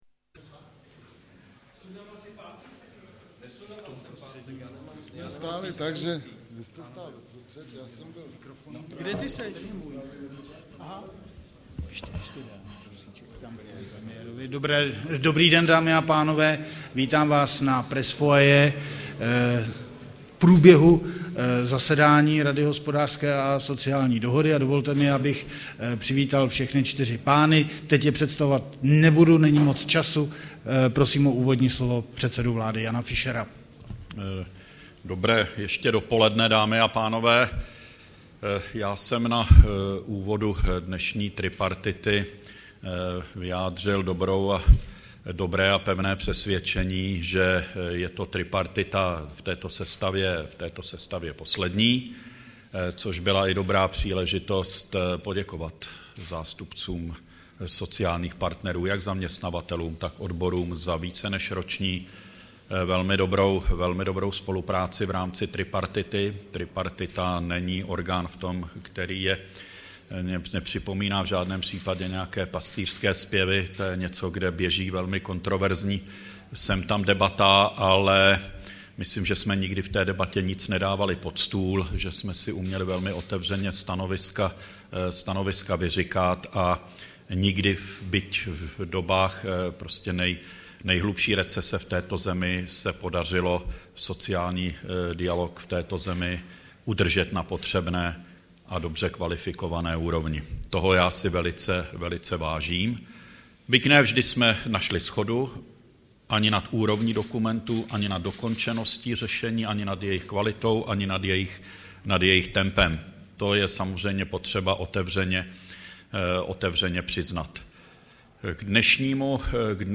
Tiskový brífink po jednání tripartity, 3. června 2010